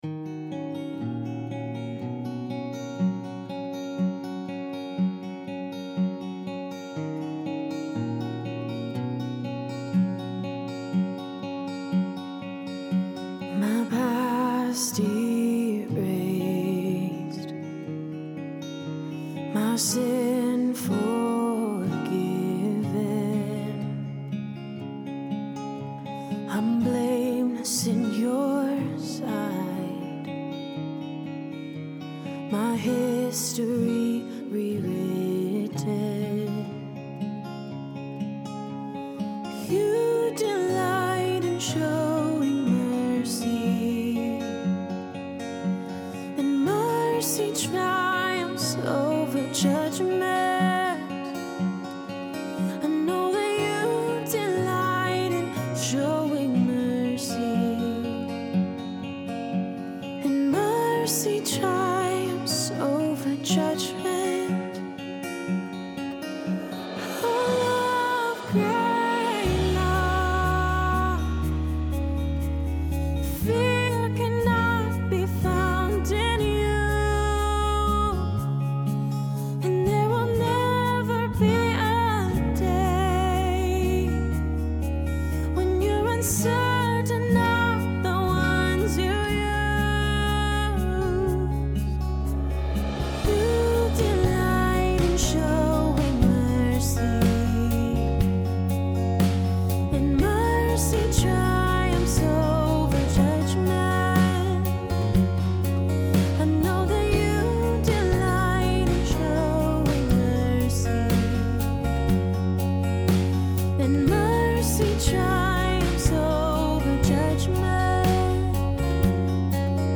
Worship:
(credits: producer, mixer, bass)